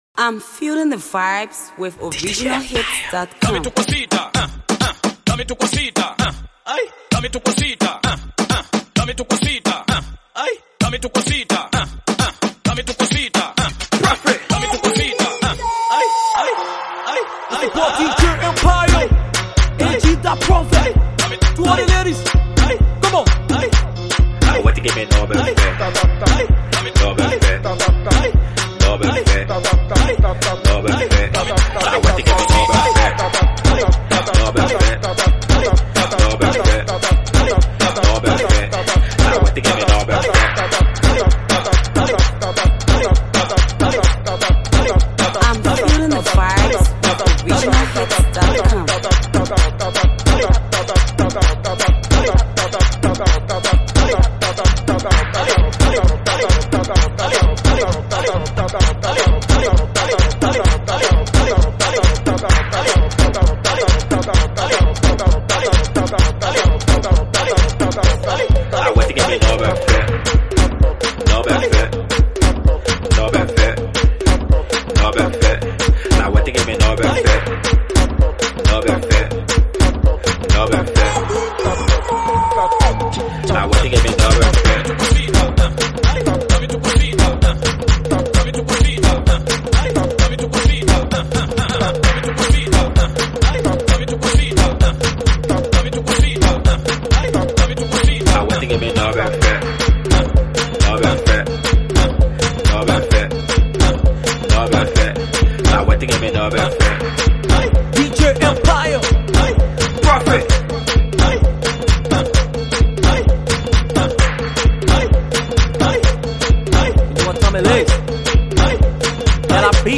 hot dancehall beat